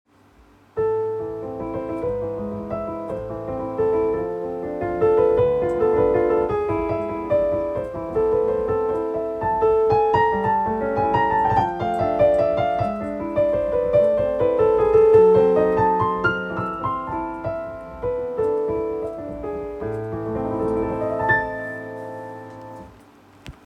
Pianoimprovisation
improvisationsexempel.mp3